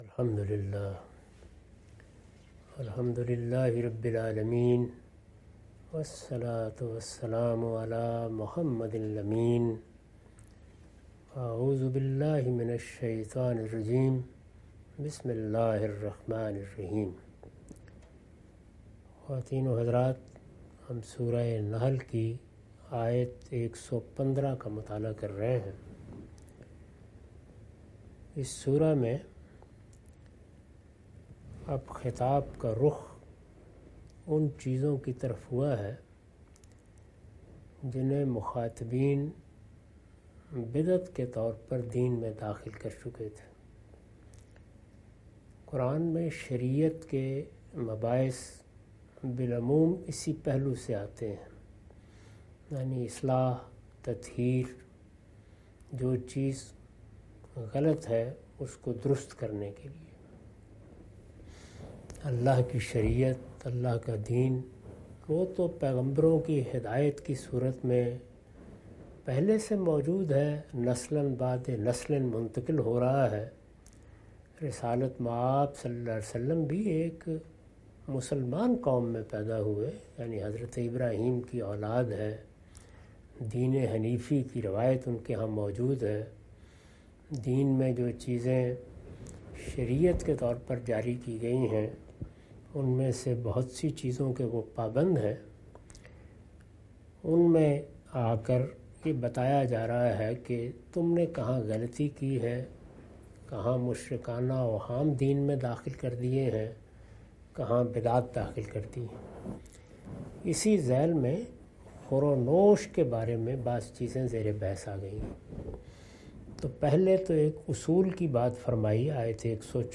Surah Al-Nahl- A lecture of Tafseer-ul-Quran – Al-Bayan by Javed Ahmad Ghamidi. Commentary and explanation of verses 115-117.